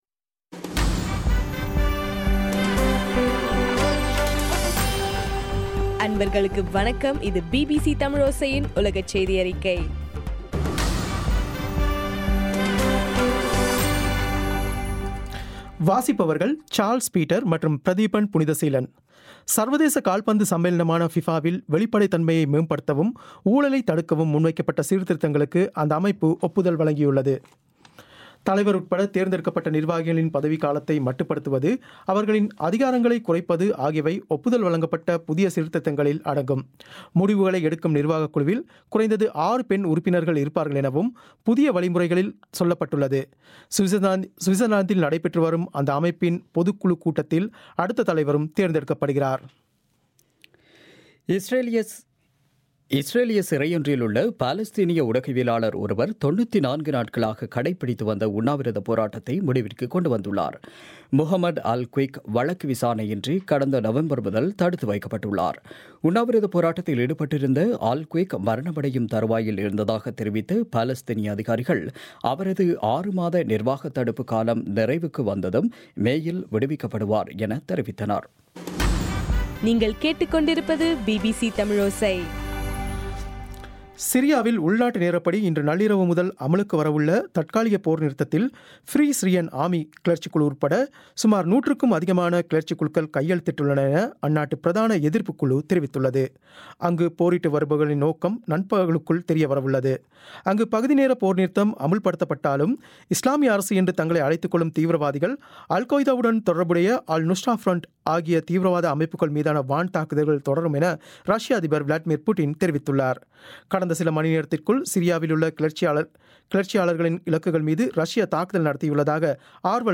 பிபிசி தமிழோசை உலகச் செய்தியறிக்கை- பிப்ரவரி 26